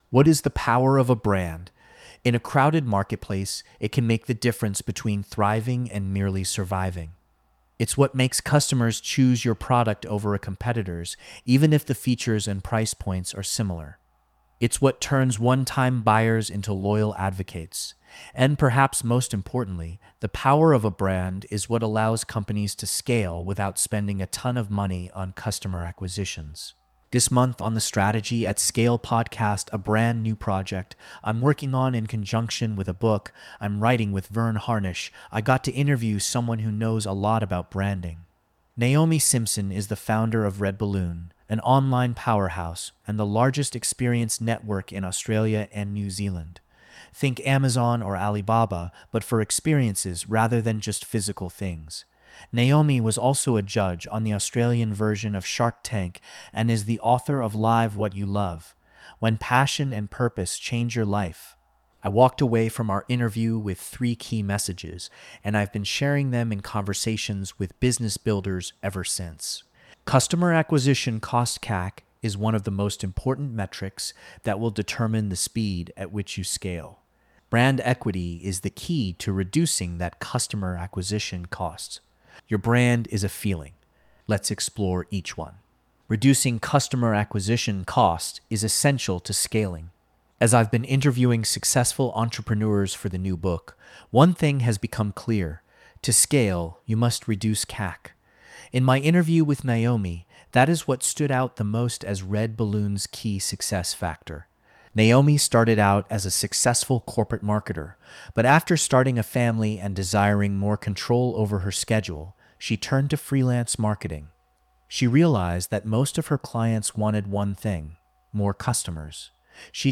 This audio was recorded by an AI program: